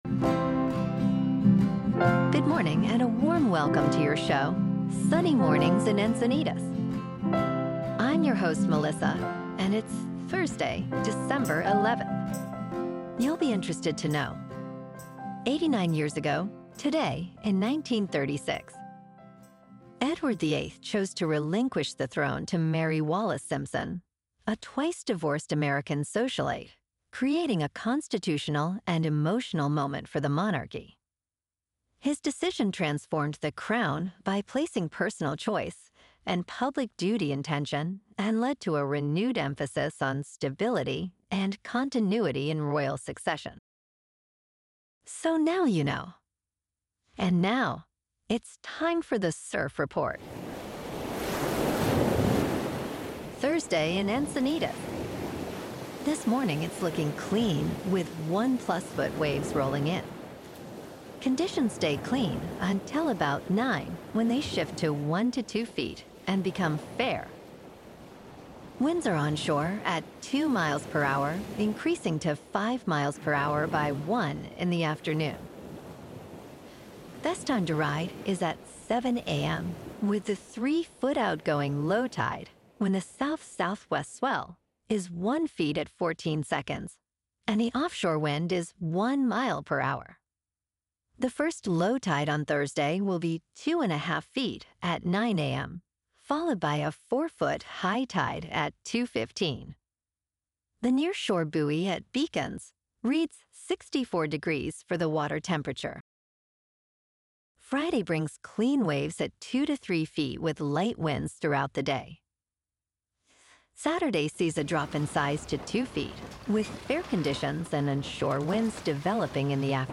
The #1 Trusted Source for AI Generated News™